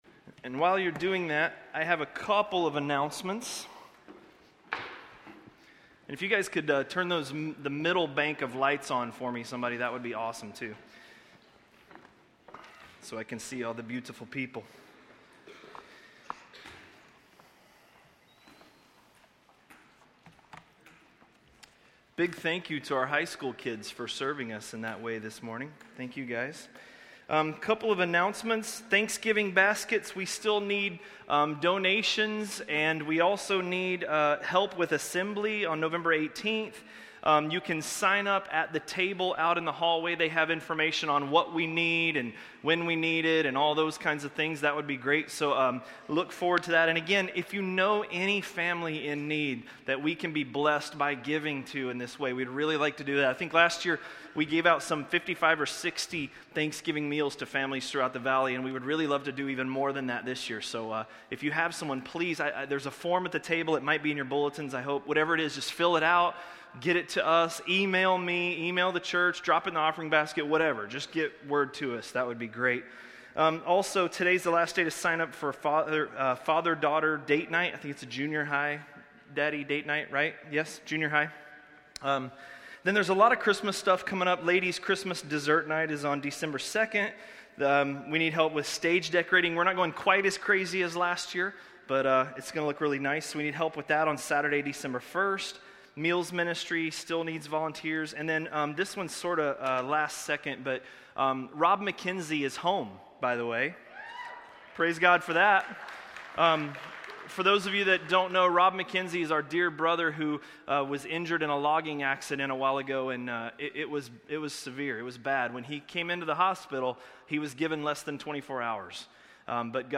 A message from the series "Romans."